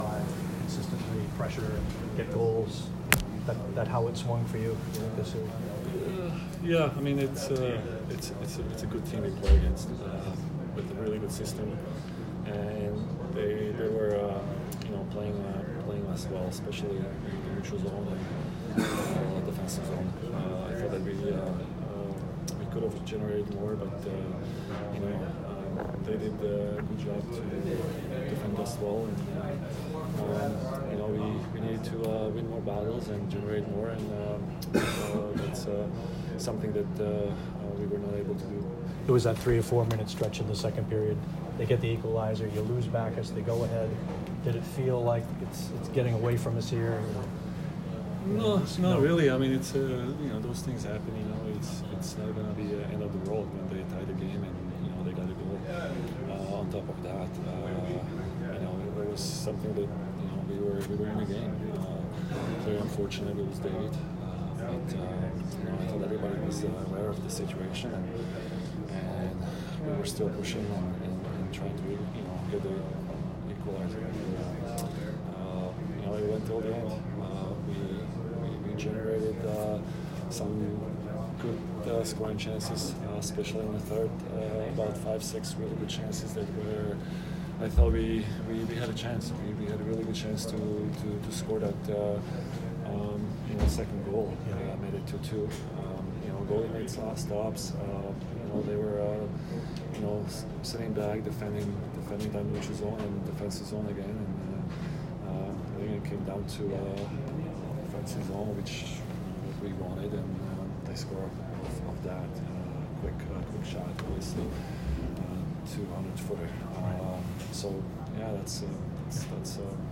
Zdeno Chara post-game 5/6